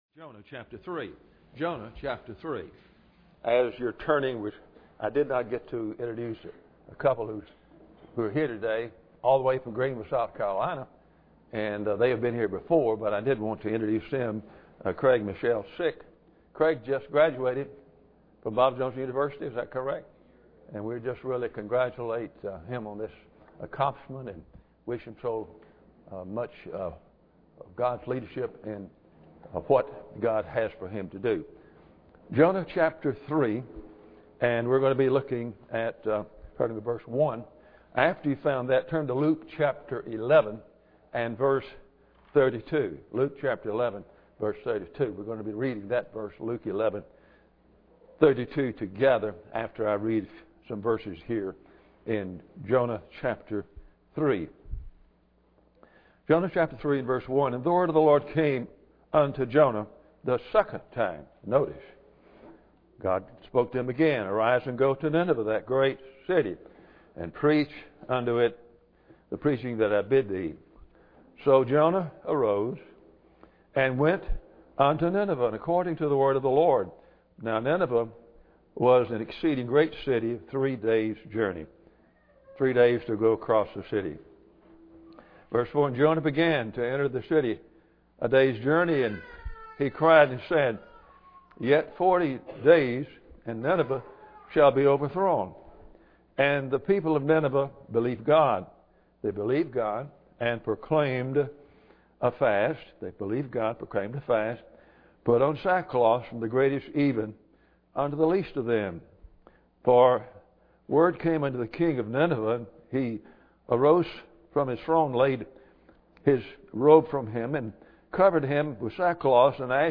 Jonah 3:1 Service Type: Sunday Morning Bible Text